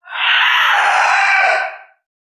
NPC_Creatures_Vocalisations_Puppet#38 (search_03).wav